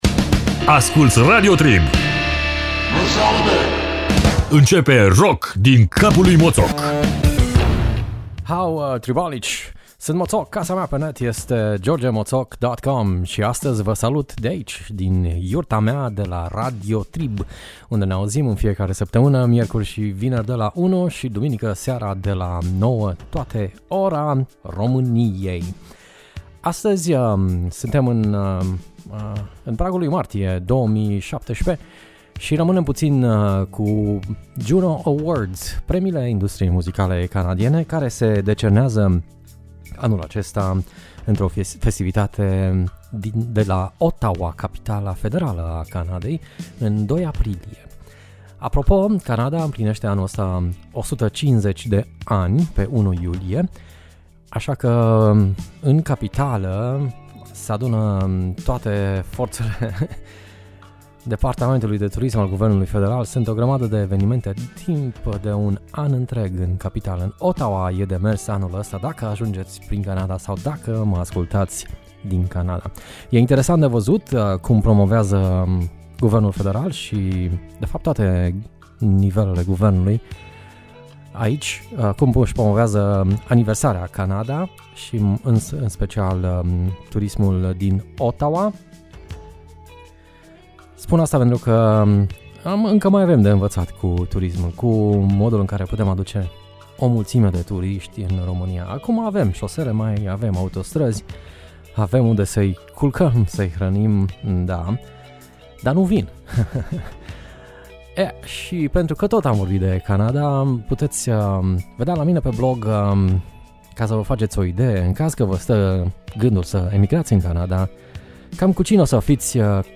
Canada e mare, mare, cat de la soul funk pana la metal.